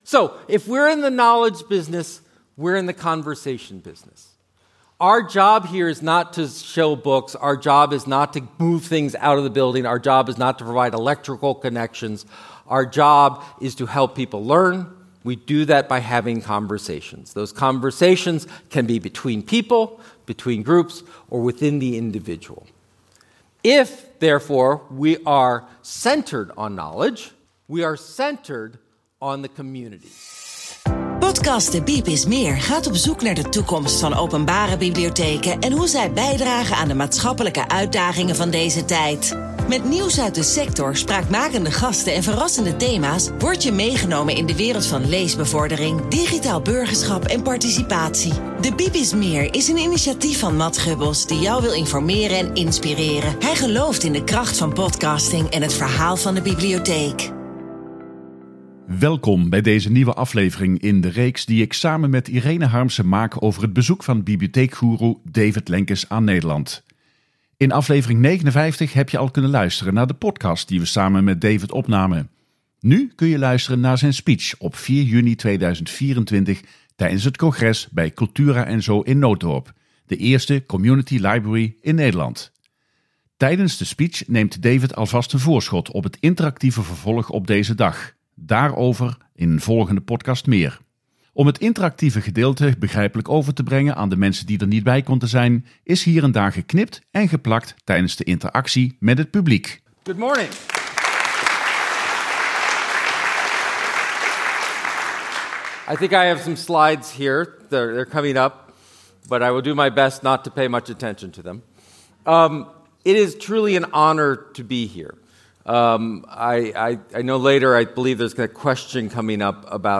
Tijdens zijn speech speelt hij met het aanwezige publiek en zet hij de toon voor het interactieve vervolg van deze dag. Hij gaat dan ook in discussie met het publiek. Om die discussie begrijpelijk te maken voor de mensen die niet aanwezig konden zijn, is - zonder afbreuk te doen aan de inhoud ervan - hier en daar geknipt en geplakt.